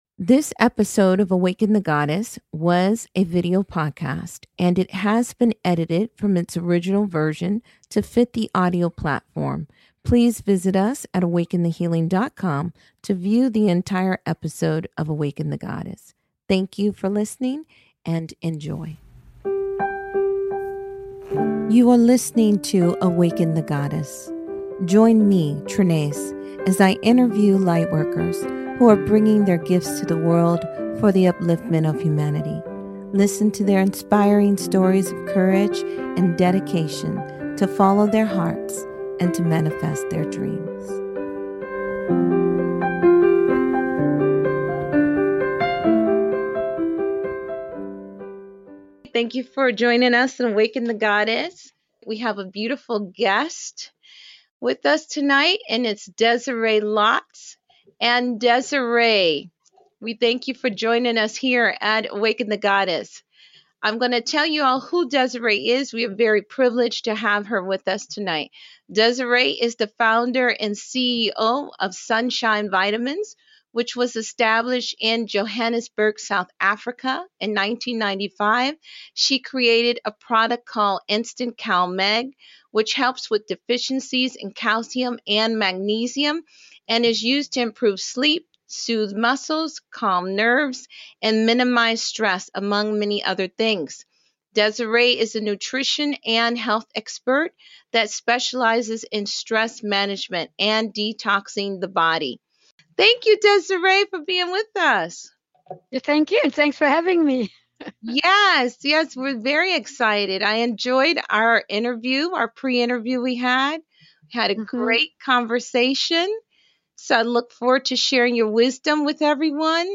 Listen to these Powerful Women, as they have a very enlightening discussion on healing and how to bring the body and mind back into balance, through proper nutrition and...